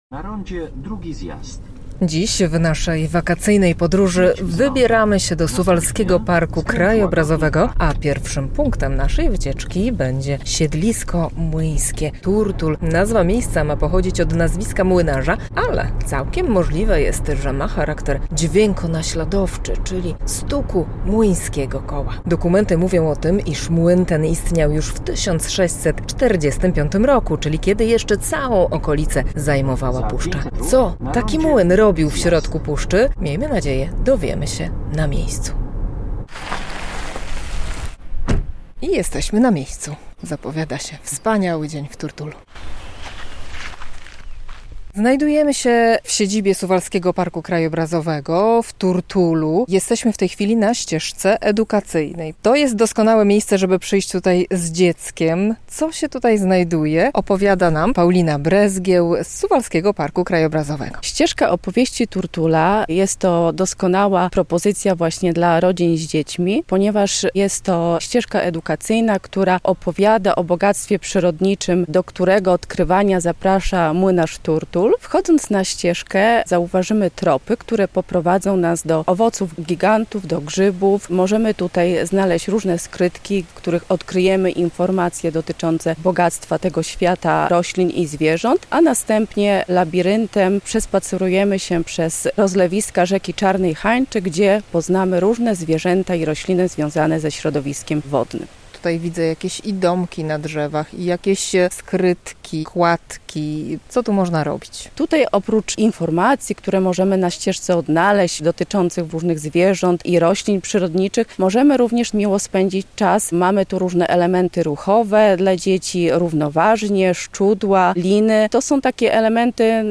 Siedlisko młyńskie Turtul - relacja